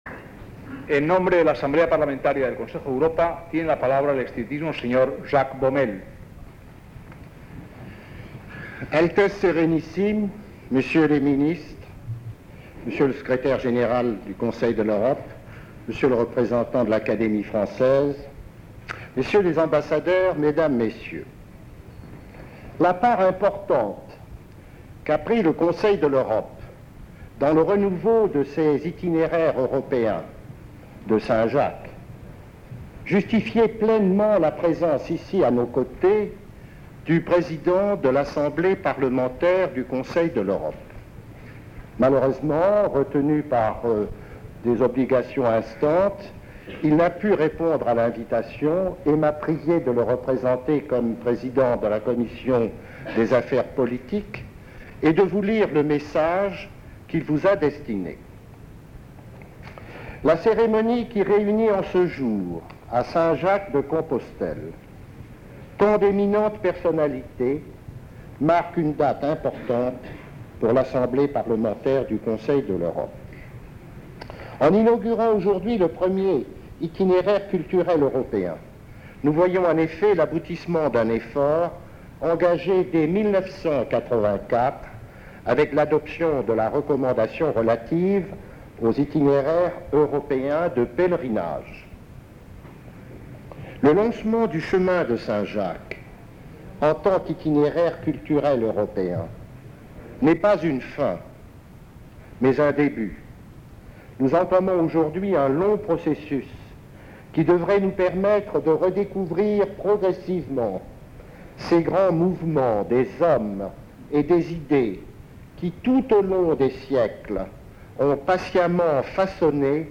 Discurso de D. Jacques Baumel
Acto de proclamación del Camino de Santiago como Itinerario Cultural Europeo. 1987
Actos de presentación